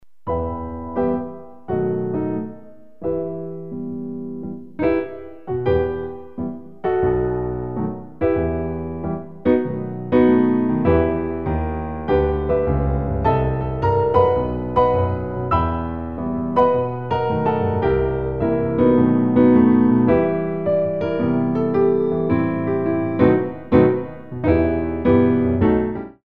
Rag